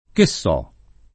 che so [ ke SS0+ ]